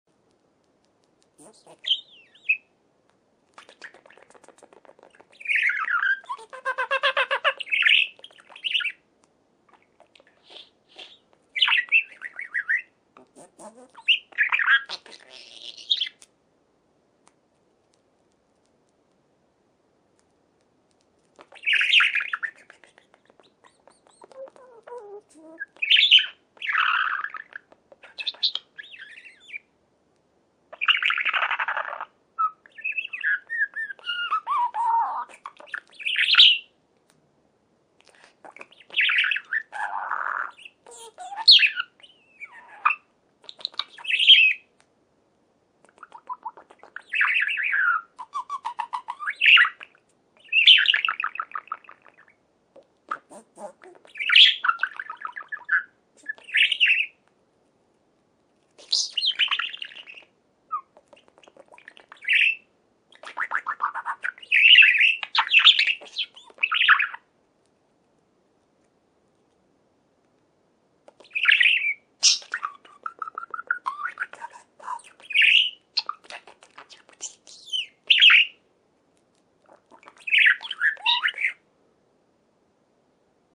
دانلود صدای مرغ عشق و چهچه زدن آن از ساعد نیوز با لینک مستقیم و کیفیت بالا
جلوه های صوتی